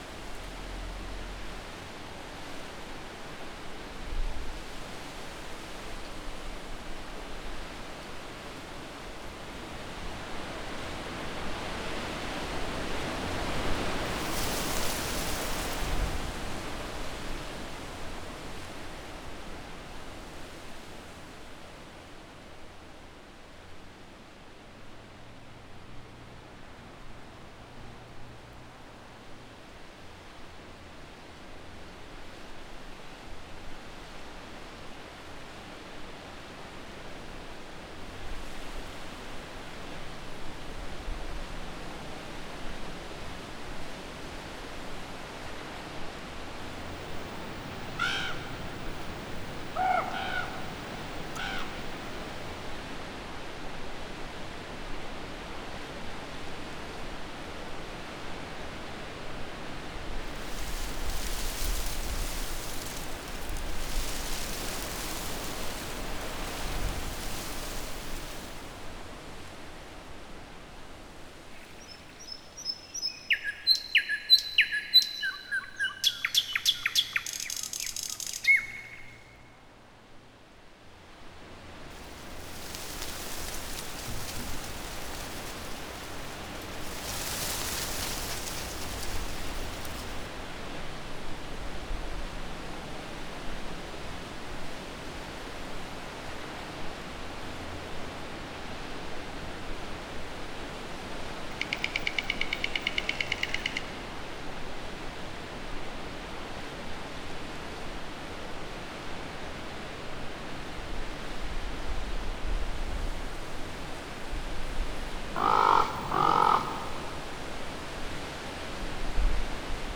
Farm_Atmos_04.wav